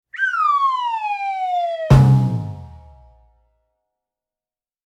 Slide Whistle